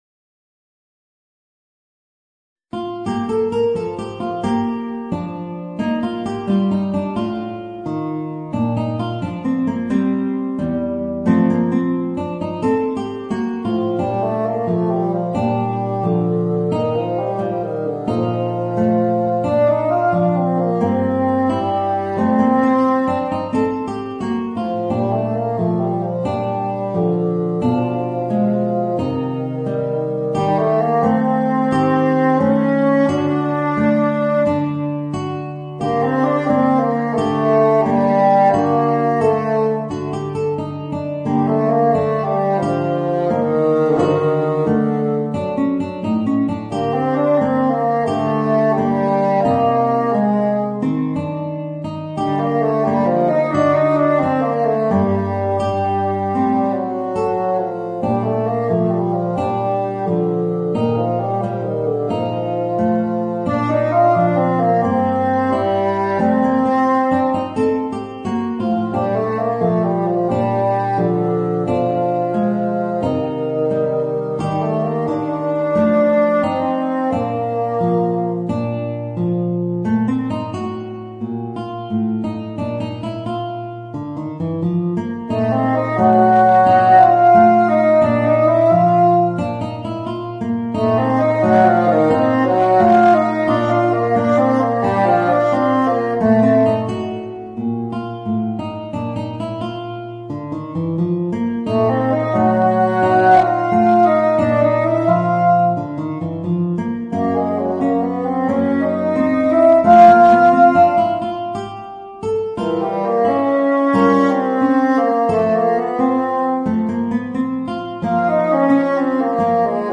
Voicing: Guitar and Bassoon